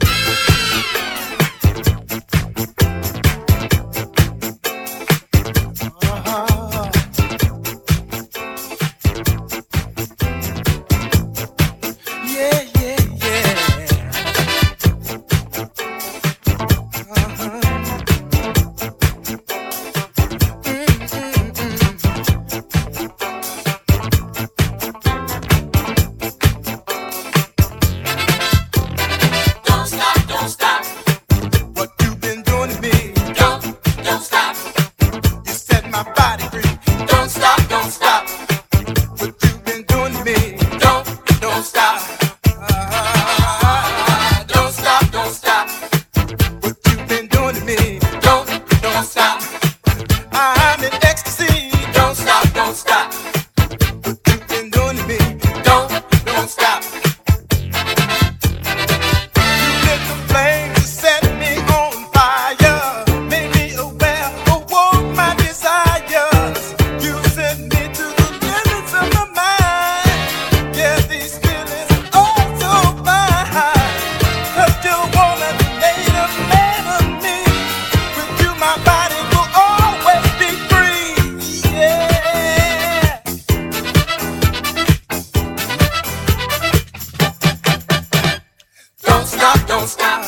SOUL / RARE GROOVE/FUNK / DISCO / DISCO FUNK
パーカッションとホーンが効いてる熱いラテン・ディスコ・ファンク